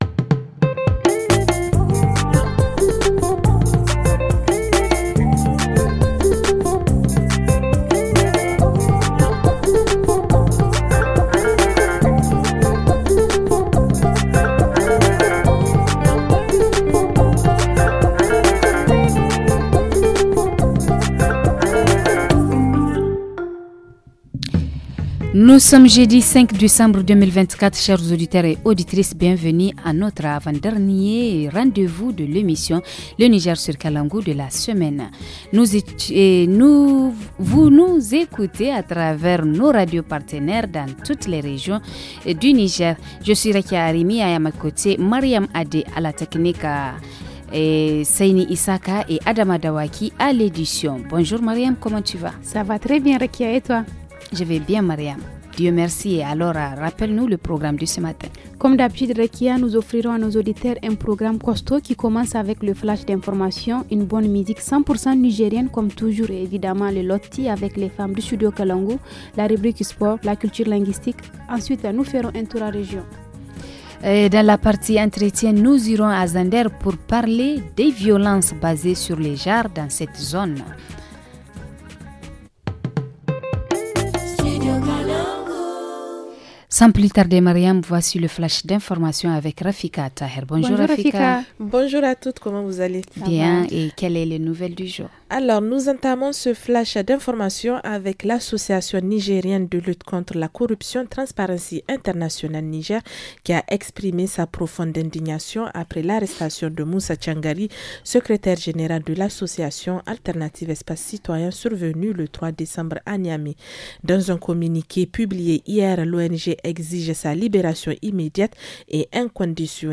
Dans la rubrique hebdomadaire, nous abordons la question de l’utilisation du téléphone portable chez les enfants. En reportage région, nous parlons de l’officialisation de la date de libération des champs à Maradi.